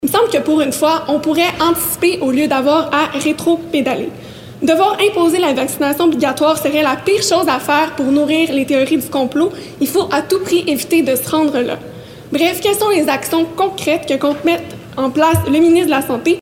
L’élue de la circonscription Marie-Victorin a fait connaître son inquiétude lors de la période de question du 3 décembre à l’Assemblée nationale.